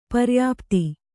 ♪ paryāpti